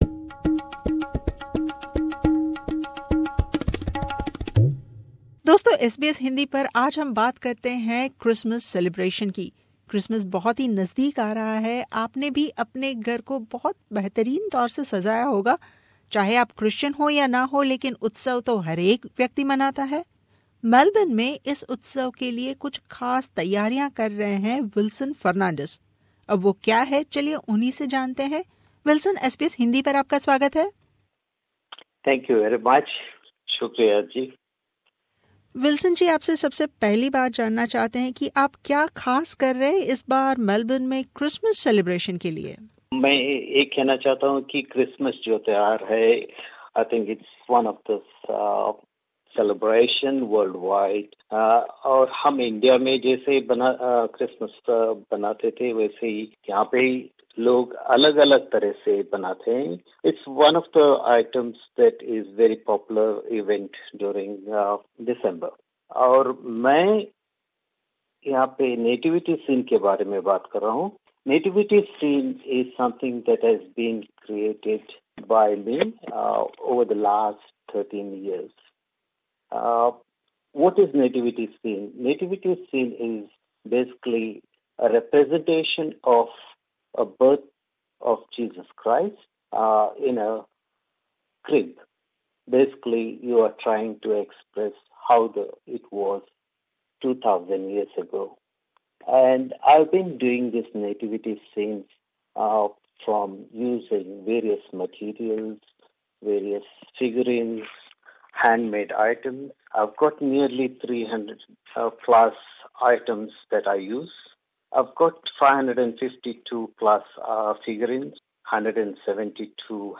मुलाकात